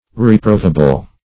Meaning of reprovable. reprovable synonyms, pronunciation, spelling and more from Free Dictionary.
Search Result for " reprovable" : The Collaborative International Dictionary of English v.0.48: Reprovable \Re*prov"a*ble\ (r?-pr??v"?-b'l), a. [Cf. F. r['e]prouvable.]
reprovable.mp3